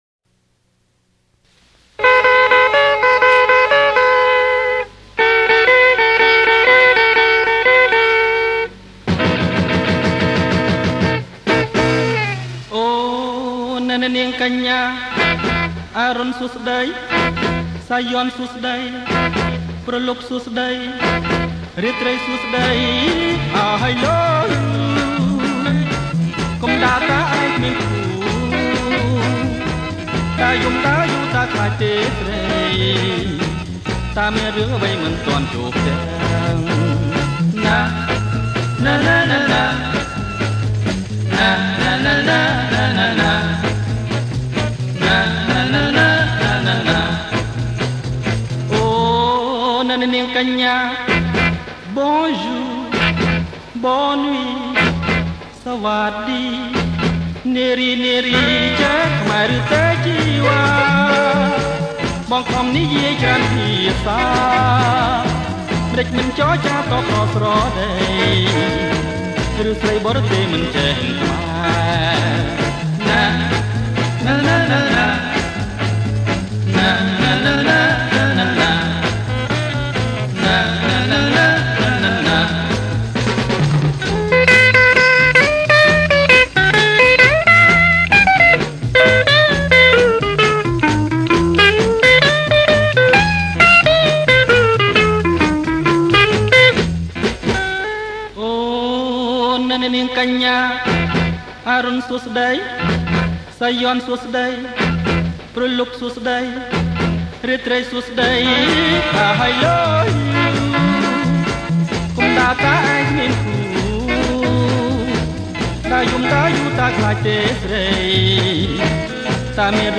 • ប្រគំជាចង្វាក់ Jerk lent
ប្រគំជាចង្វាក់ Jerk lent